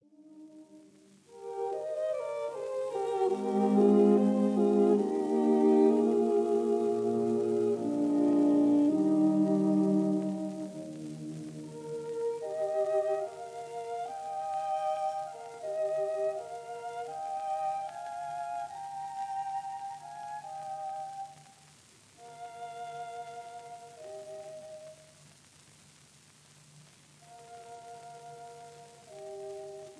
violins
viola
cello
in E-flat major — Largo